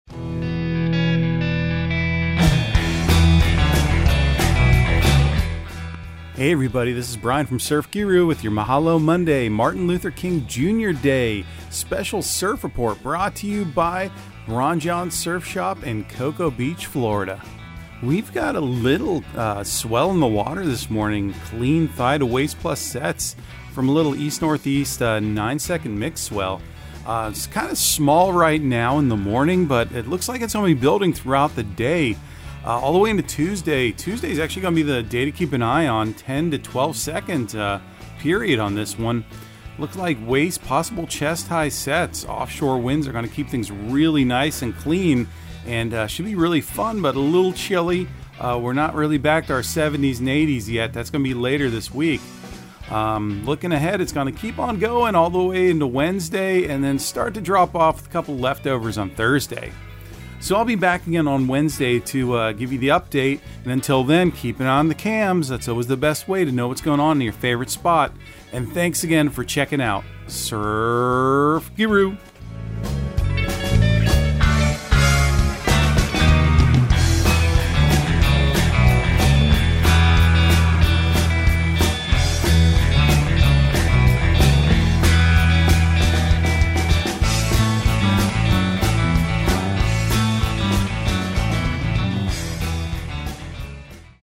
Surf Guru Surf Report and Forecast 01/16/2023 Audio surf report and surf forecast on January 16 for Central Florida and the Southeast.